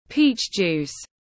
Nước ép quả đào tiếng anh gọi là peach juice, phiên âm tiếng anh đọc là /piːtʃ ˌdʒuːs/
Peach juice /piːtʃ ˌdʒuːs/